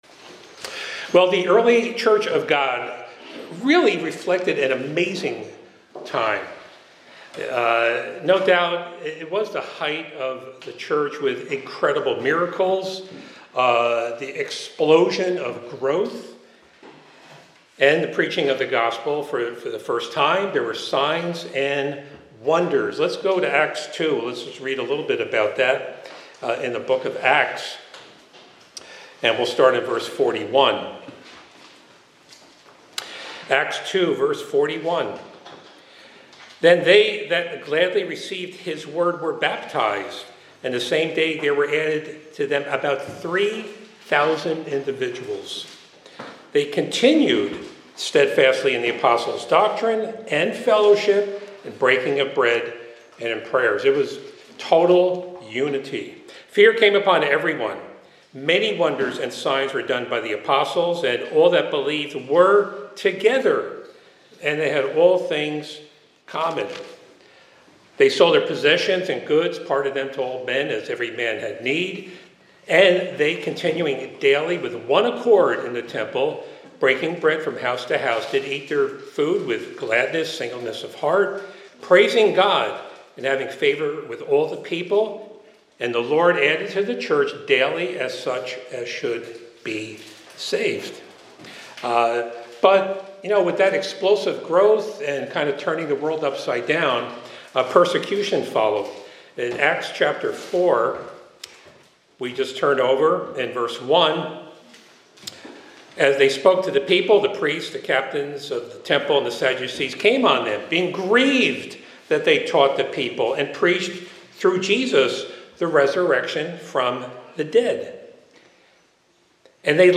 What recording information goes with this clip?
Given in Hartford, CT